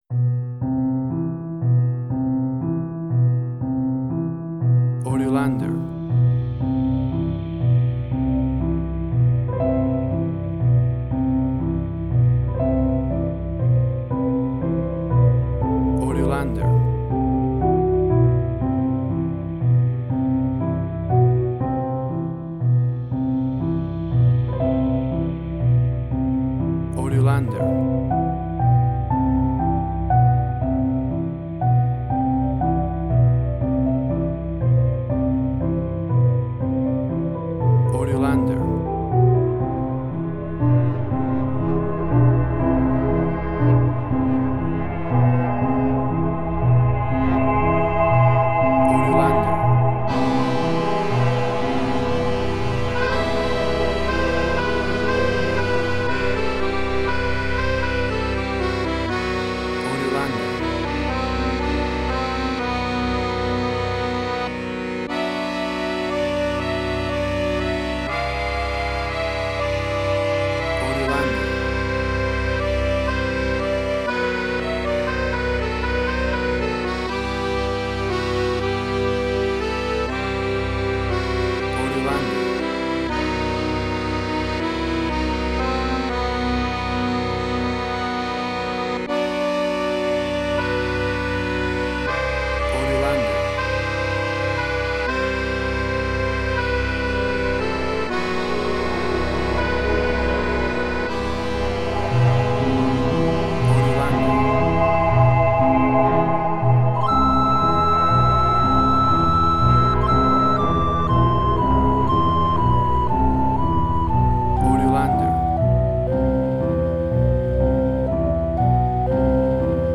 Ambient Strange&Weird.
Tempo (BPM): 120